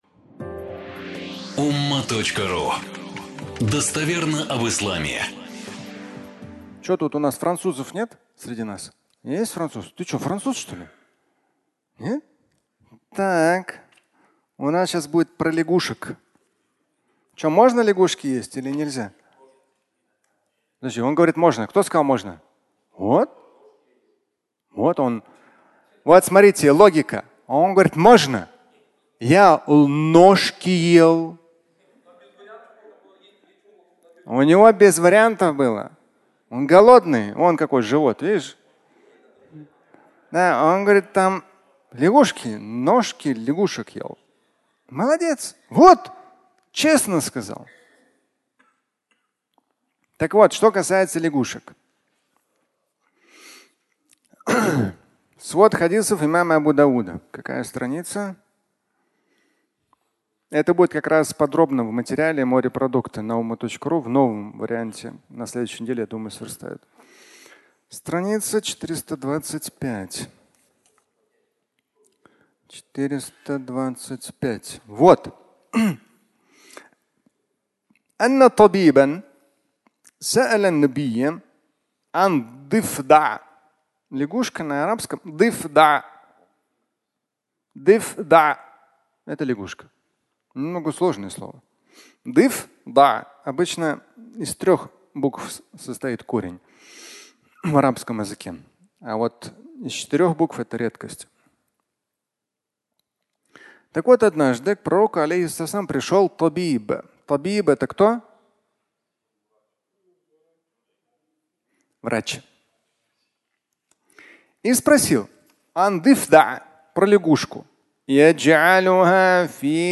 Лягушка (аудиолекция)